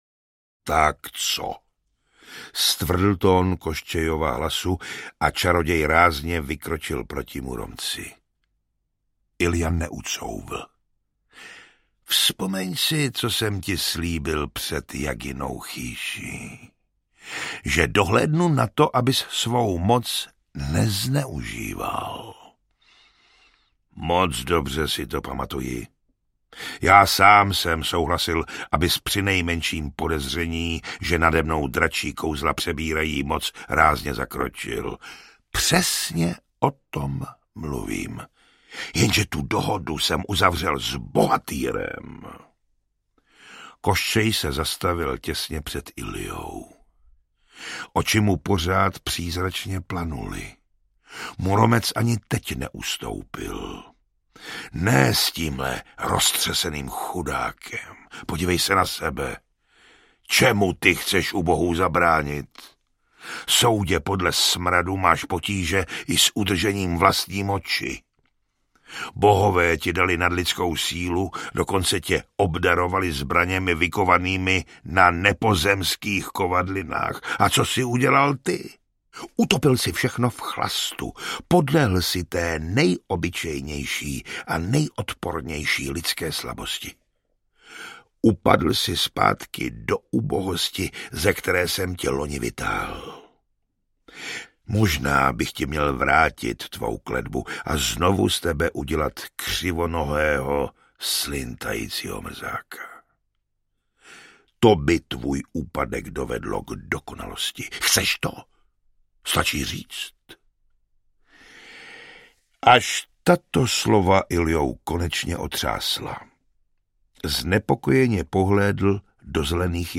Audiobook
Audiobooks » Fantasy & Sci-fi